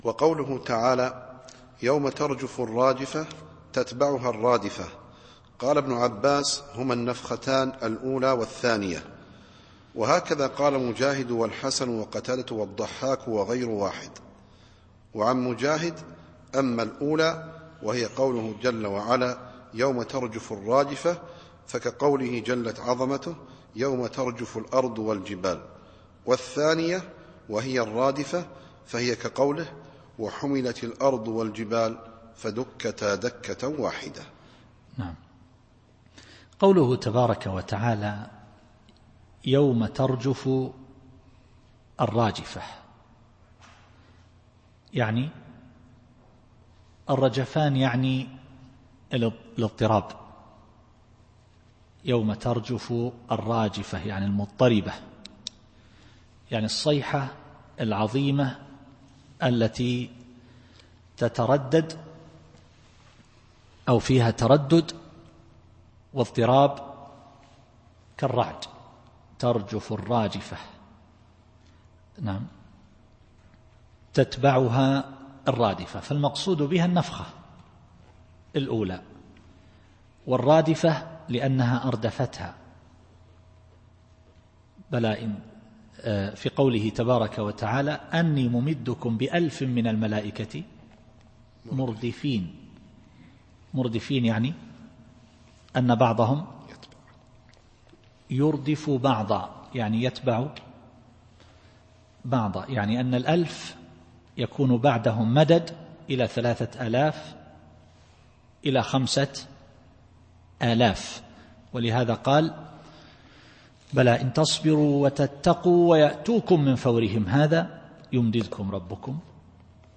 التفسير الصوتي [النازعات / 7]